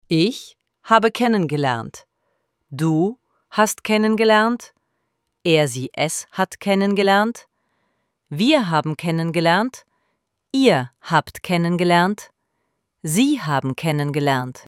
IZGOVOR – KENNENLERNEN:
ElevenLabs_Text_to_Speech_audio-67.mp3